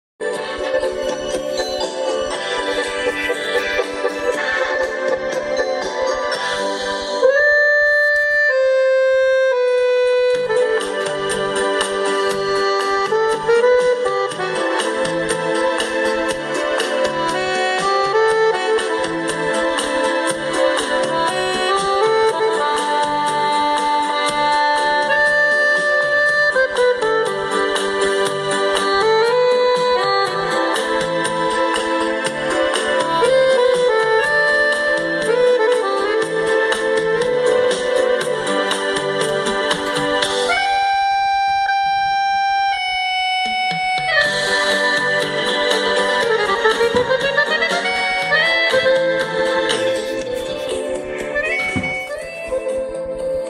ID исполнителя пожалуйста (инструментальная версия "O Sole Mio" )